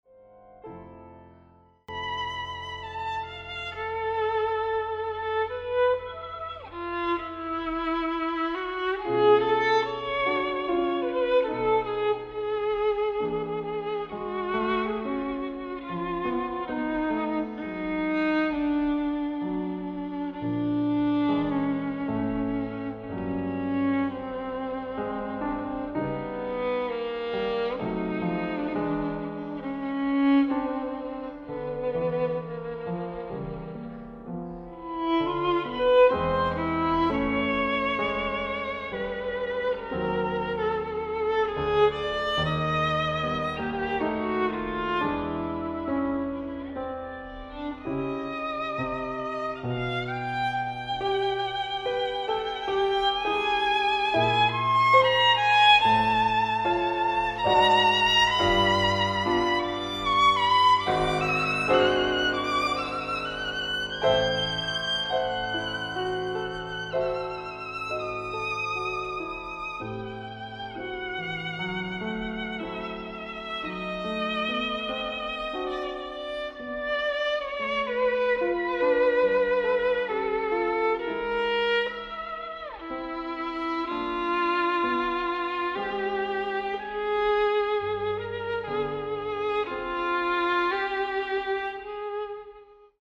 Archival Recordings
Andante 5:39